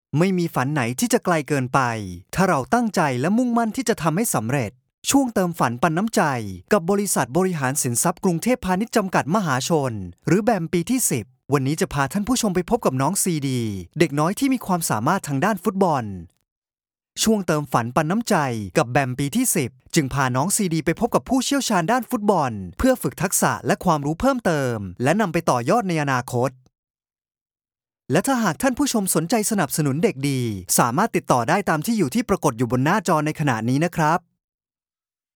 年轻亲和